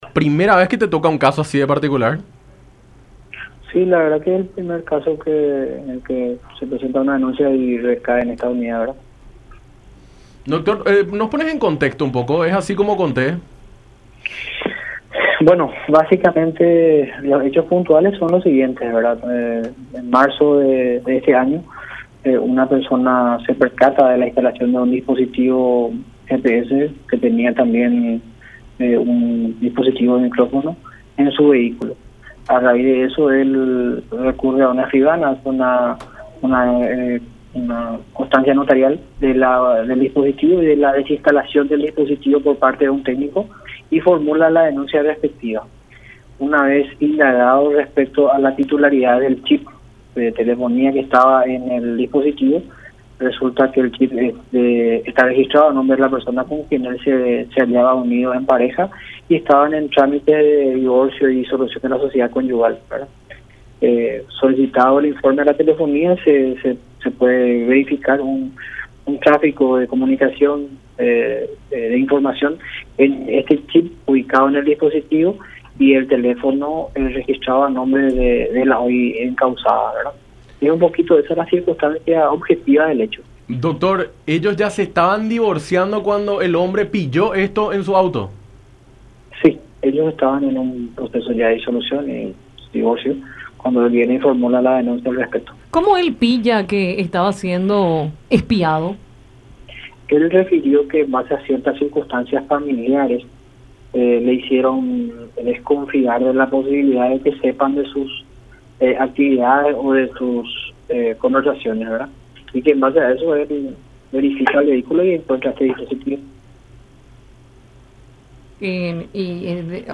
“El hombre descubrió ciertas circunstancias familiares y conversaciones que le hicieron desconfiar de que probablemente lo estaban espiando. Verificó su vehículo y encontró un GPS escondido. Recurrió a una escribanía para dejar constancia de esta situación y pidió la desinstalación del equipo por parte de un técnico, para luego formular la denuncia respectiva”, dijo el fiscal del caso, Juan Olmedo, en conversación con Enfoque 800 por La Unión.